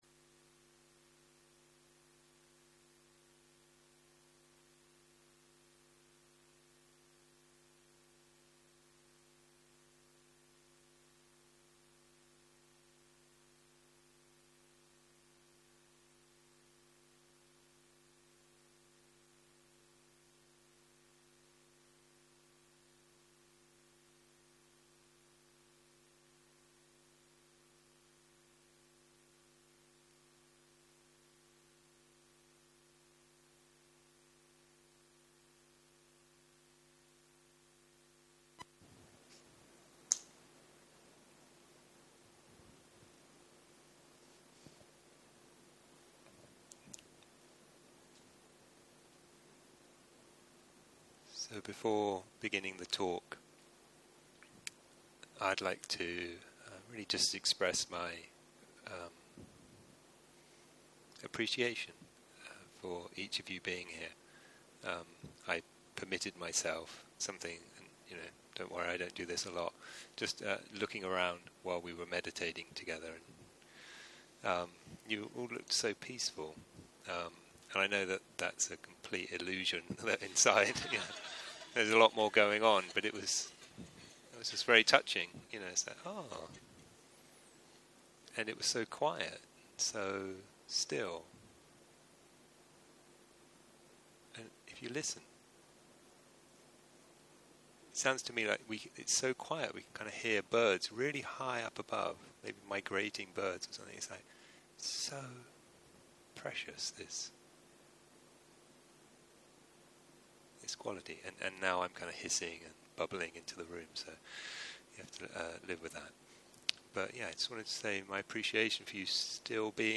11.04.2023 - יום 5 - ערב - שיחת דהרמה - All phenomena is unsatisfactory - הקלטה 17
סוג ההקלטה: שיחות דהרמה